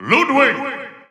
The announcer saying Ludwig's name in English and Japanese releases of Super Smash Bros. 4 and Super Smash Bros. Ultimate.
Ludwig_English_Announcer_SSB4-SSBU.wav